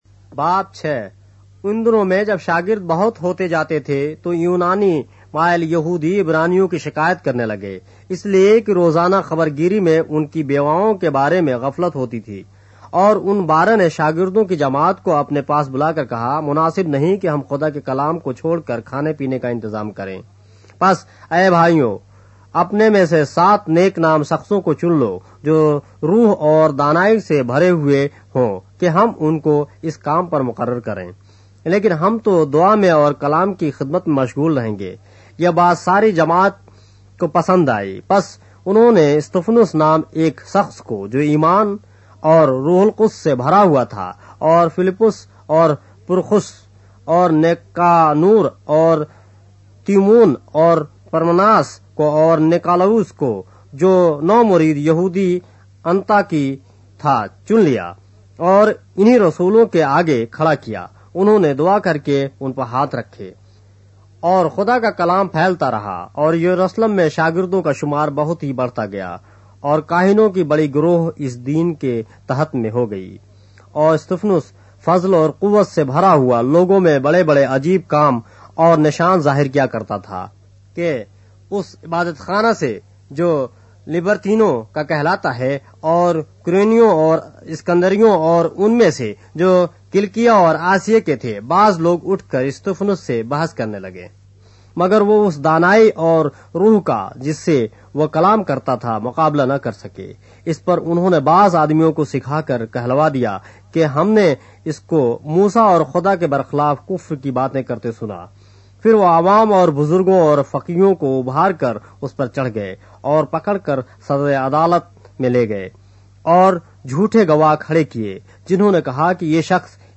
اردو بائبل کے باب - آڈیو روایت کے ساتھ - Acts, chapter 6 of the Holy Bible in Urdu